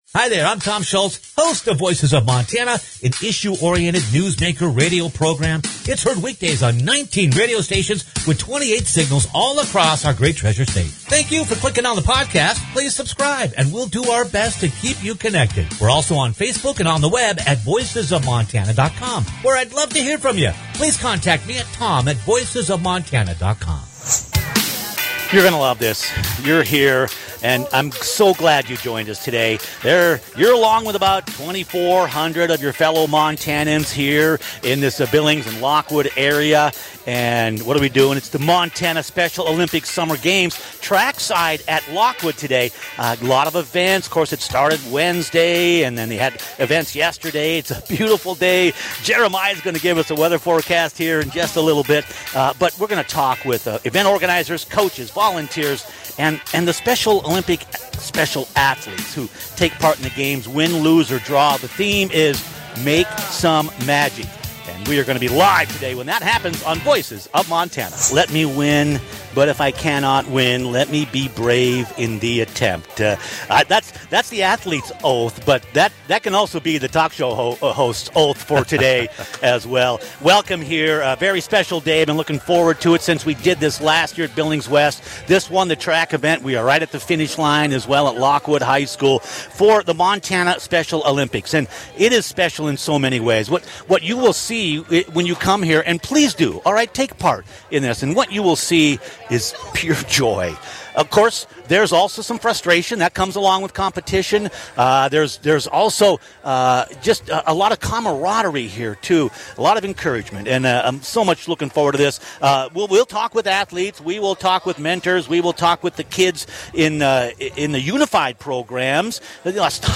Come join us and about 2,000 of your fellow Montanans for the Montana Special Olympics Summer Games, as we broadcast live track-side from Lockwood high school for the events Friday morning, talking with event organizers, coaches, volunteers, and the special athletes who take part in the games.